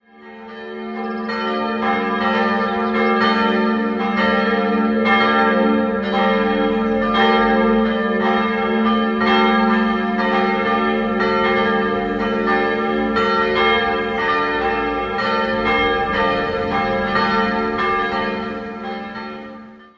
Die Pfarrkirche St. Michael ist eine gotische Chorturmkirche, vermutlich aus dem frühen 15. Jahrhundert stammend, besitzt aber heute eine barocke Ausstattung.. 1758 wurde sie nach Westen verlängert und der baufällige Kirchturm repariert und erhöht. 3-stimmiges Geläute: g'-a'-d'' Alle Glocken wurden von der Familie Glockengießer in Nürnberg gegossen: Eine stammt aus der Zeit um 1500, die anderen beiden von 1566 und 1567.